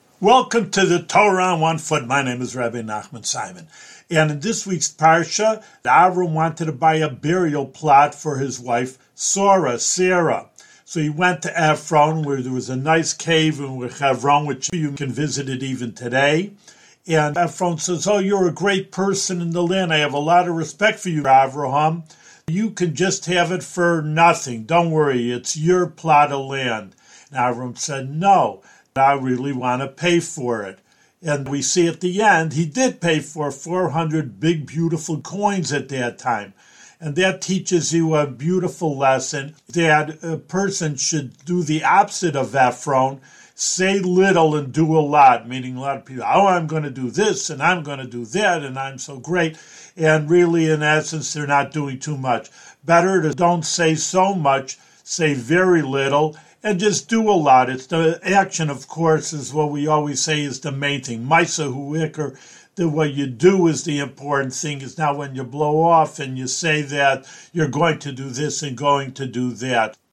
One-minute audio lessons on special points from weekly Torah readings in the Book of Genesis.